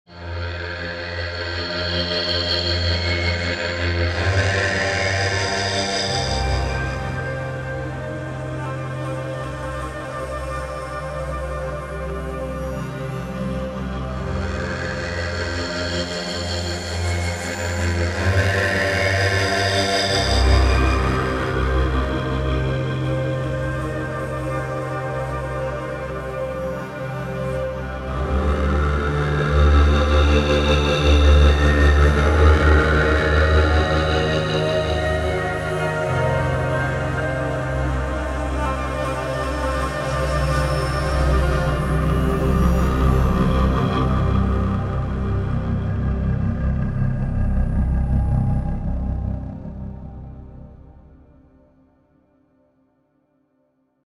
Scary Ambience